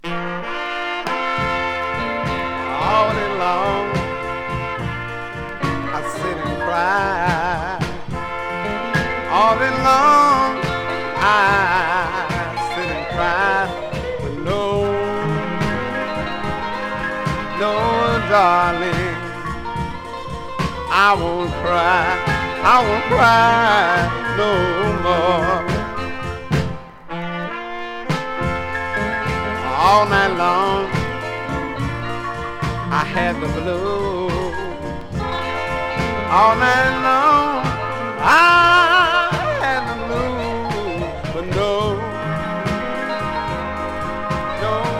Zydeco　UK　12inchレコード　33rpm　Mono